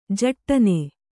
♪ jaṭṭane